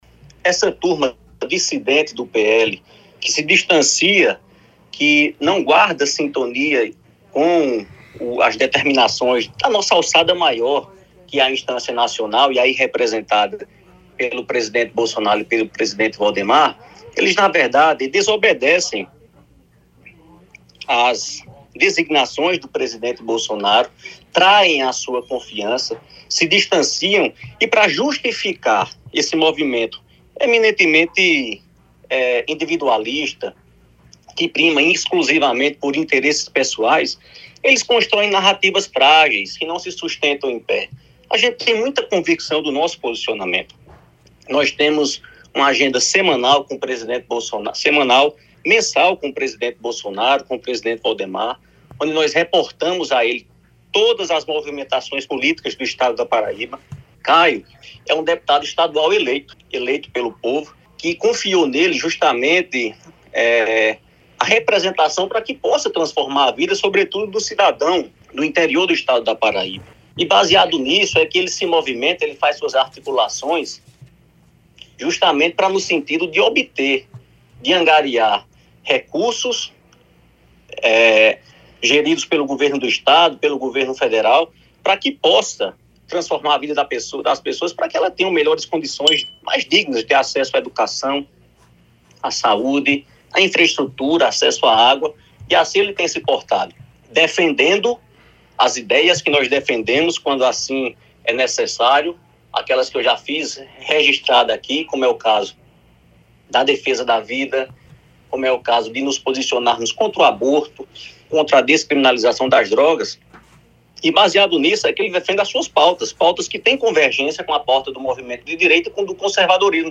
Os comentários do ex-parlamentar foram registrados pelo programa Correio Debate, da 98 FM, de João Pessoa, nesta quarta-feira (08/11).